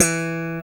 Index of /90_sSampleCDs/Roland LCDP02 Guitar and Bass/BS _Funk Bass/BS _5str v_s